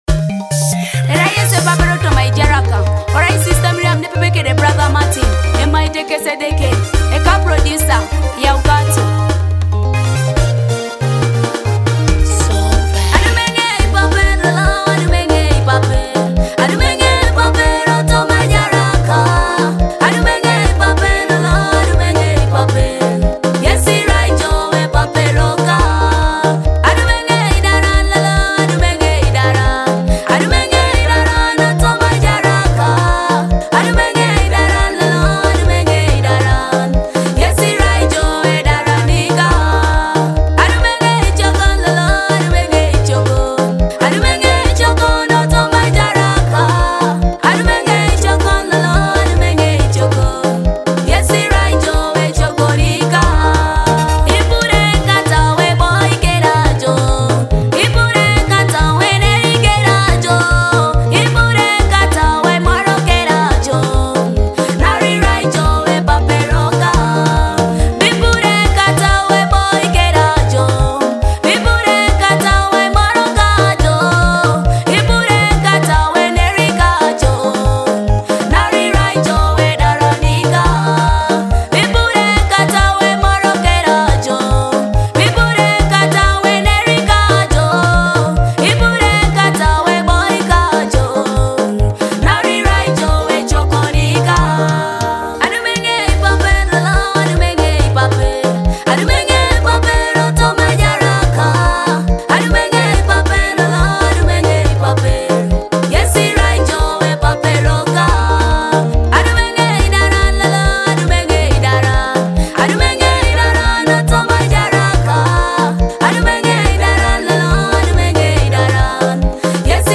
uplifting gospel hit